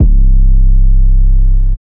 808_Oneshot_Chaser_C
808_Oneshot_Chaser_C.wav